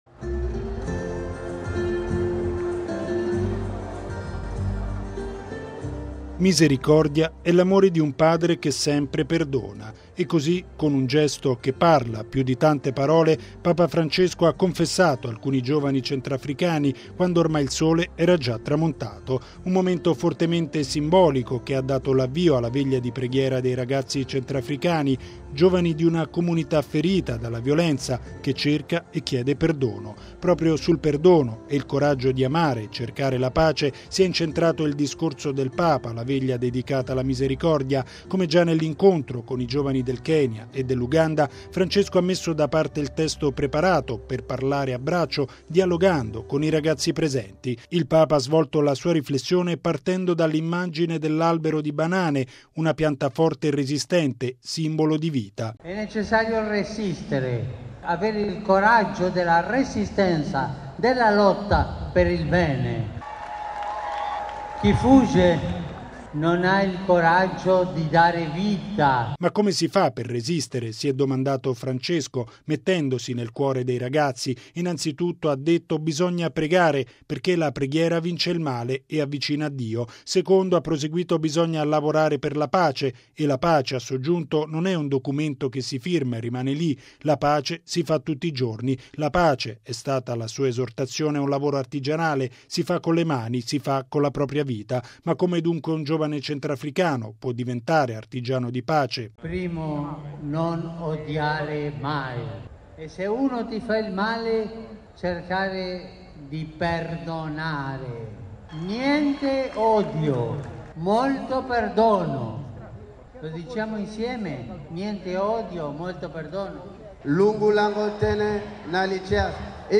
Conclusa la Messa nella Cattedrale di Bangui, Papa Francesco ha dato avvio alla Veglia di Preghiera dei giovani dedicata alla Misericordia.